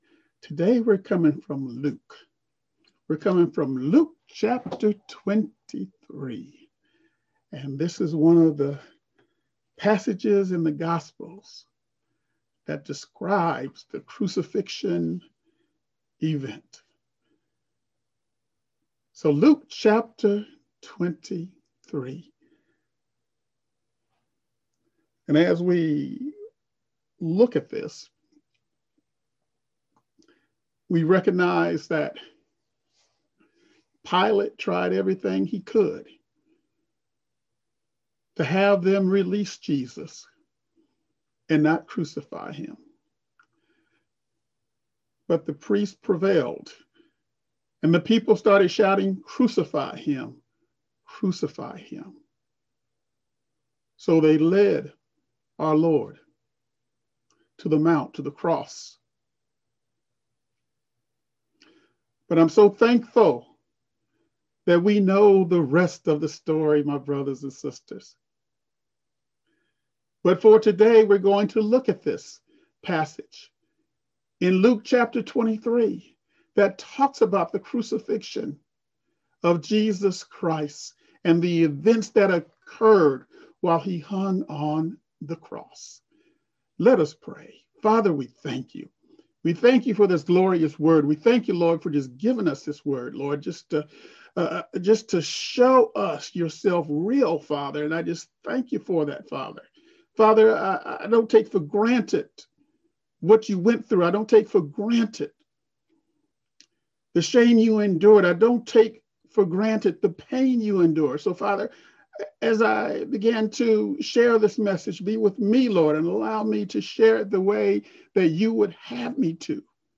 In today's message from Luke 23, we'll examine the events that occurred while Jesus hung on the cross.